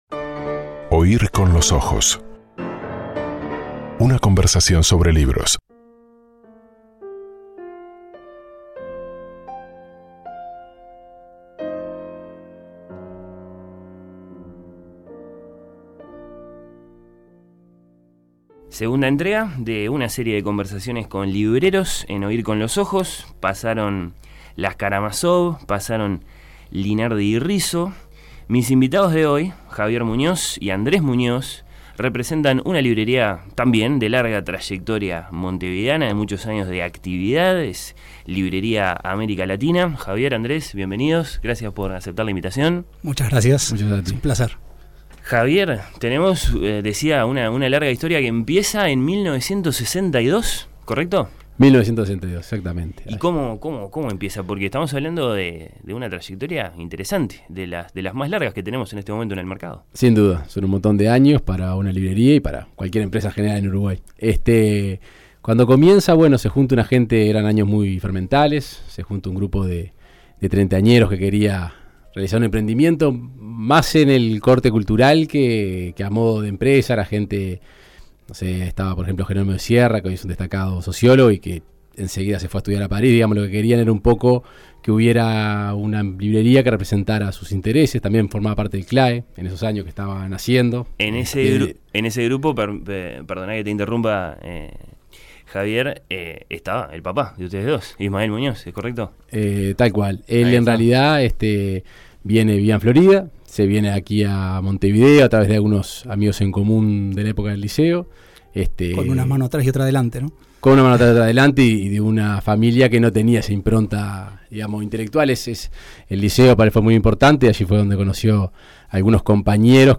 Conversaciones con libreros.